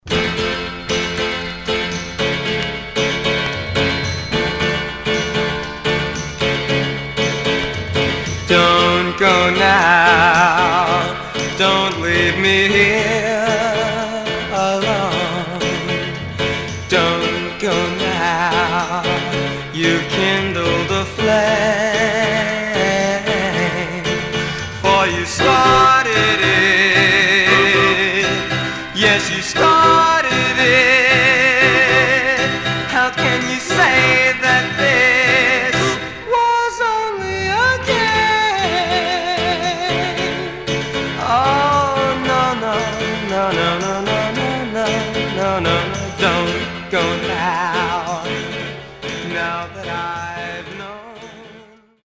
Teen Condition: M- DJ
Stereo/mono Mono